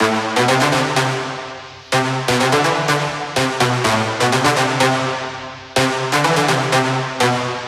Index of /99Sounds Music Loops/Instrument Loops/Brasses